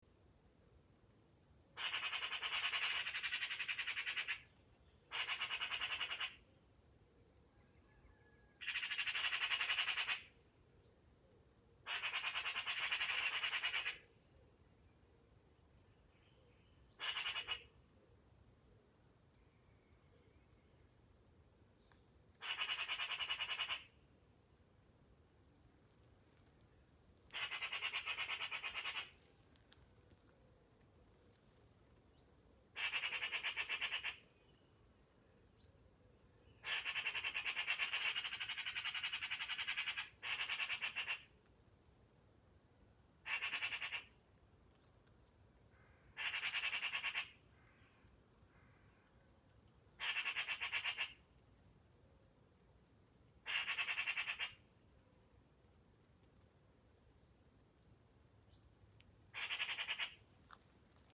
Звук сороки скачать и слушать онлайн
Здесь вы можете прослушать онлайн какие звуки издаёт сорока и скачать mp3 бесплатно и без регистрации.
soroka-sw.mp3